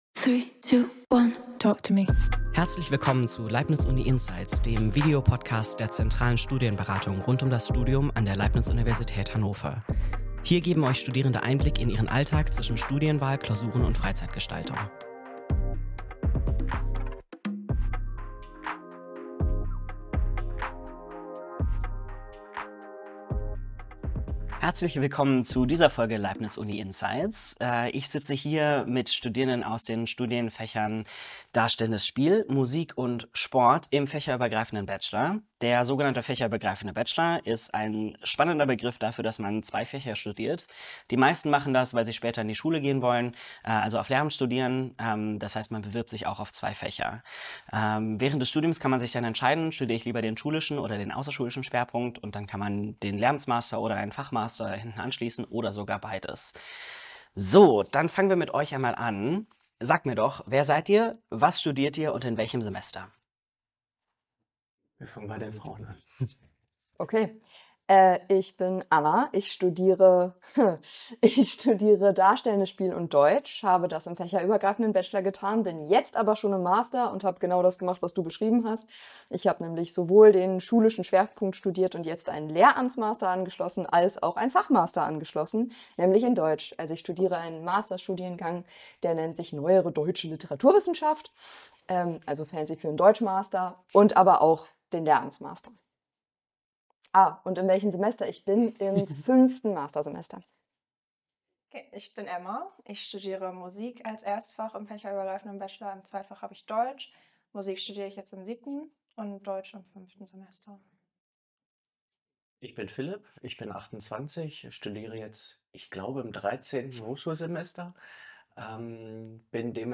Beschreibung vor 9 Monaten In dieser Folge von "Leibniz Uni Insights" diskutieren Studierende der Leibniz Universität Hannover ihre Erfahrungen in den Studiengängen Musik, Sport und Darstellendes Spiel, die alle eine Aufnahmeprüfung erfordern. Sie erläutern den Ablauf und die Vorbereitung dieser Prüfungen und geben Einblicke in ihren Studienalltag, einschließlich des Mixes aus theoretischem Unterricht und praktischen Projekten.